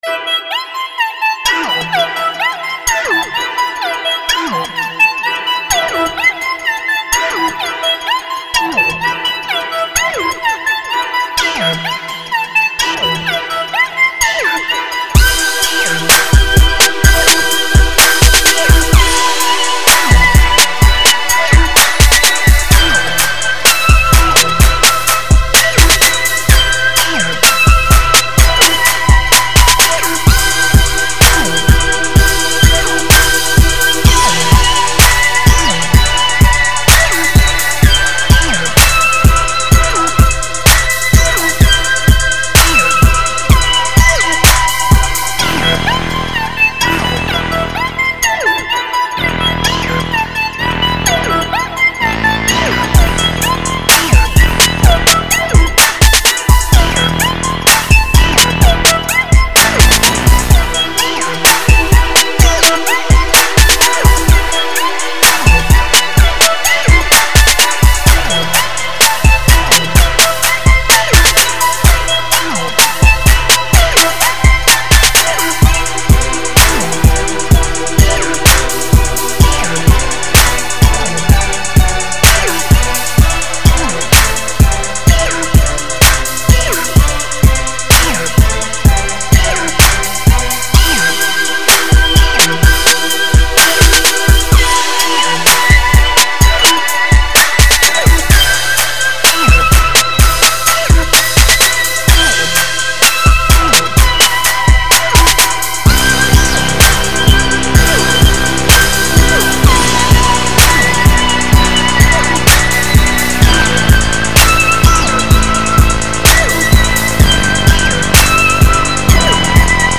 Street track, Airy sounds. 127 BPM